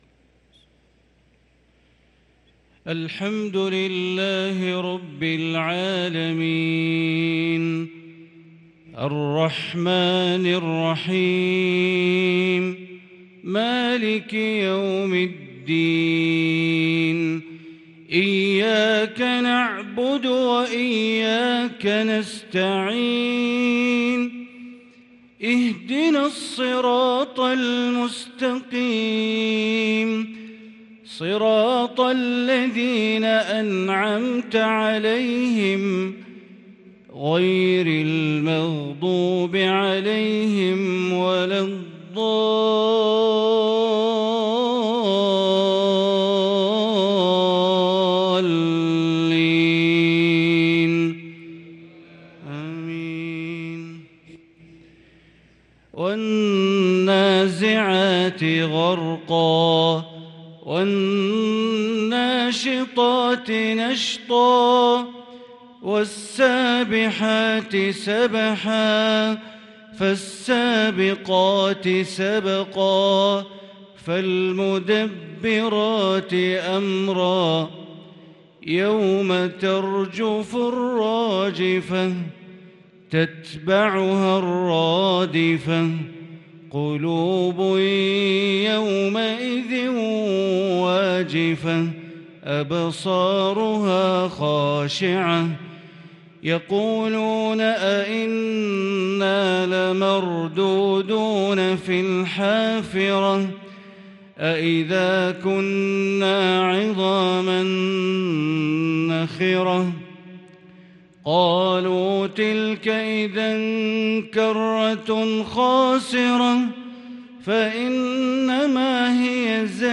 صلاة العشاء للقارئ بندر بليلة 22 ربيع الآخر 1444 هـ
تِلَاوَات الْحَرَمَيْن .